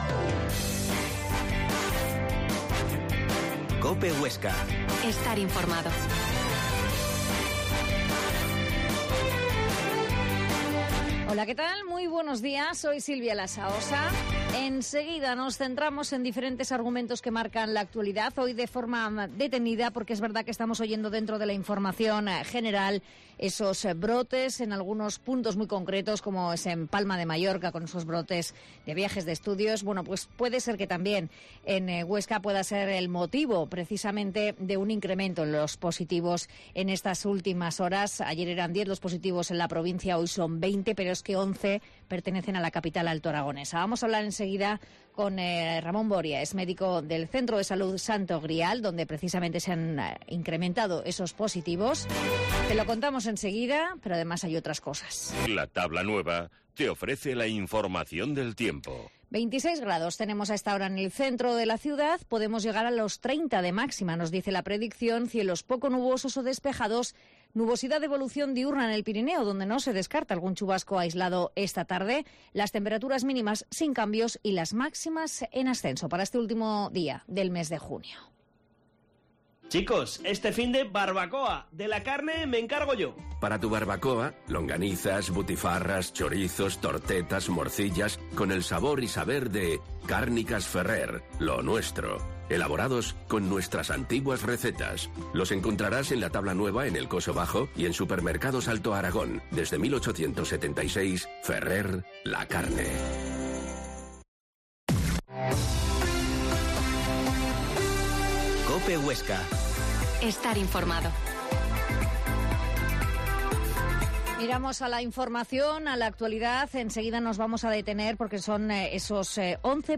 Herrera en COPE Huesca 12.50h Entrevista